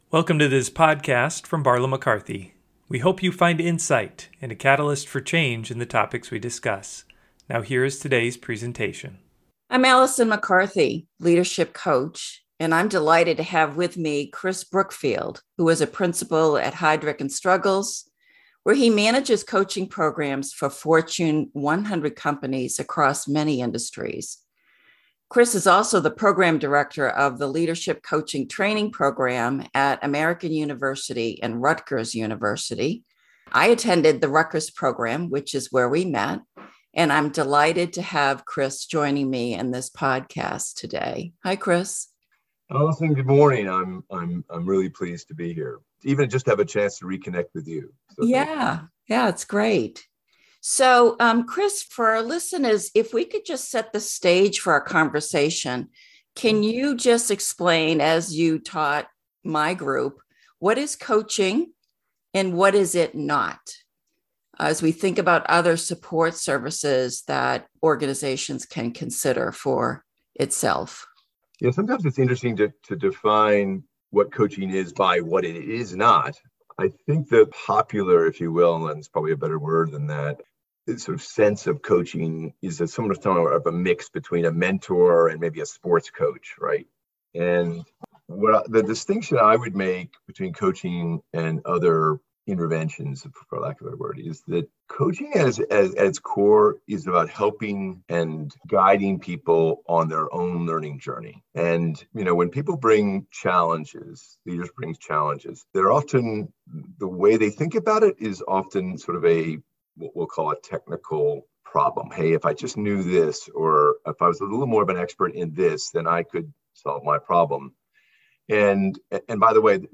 Coaching’s role is to guide and support people in these learning journeys. This interview shares more insights on the role of the coach and its benefit to those working to grow and advance their ability to lead.